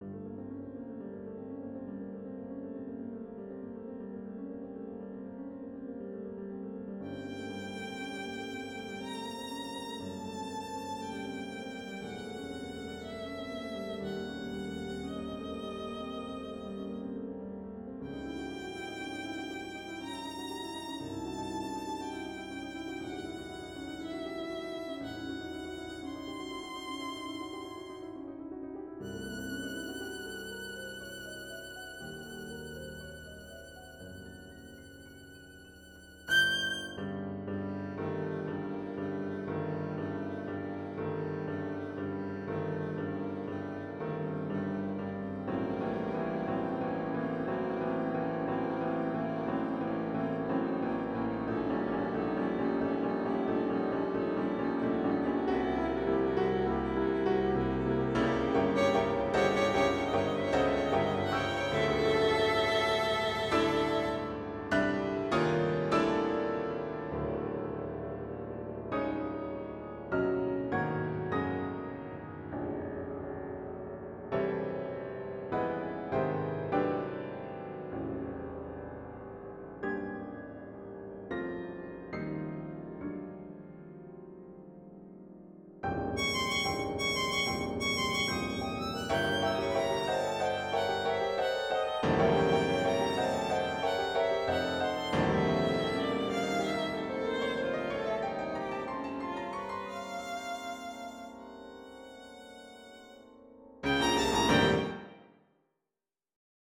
Violin and piano duet; based on villain from Book of Mormon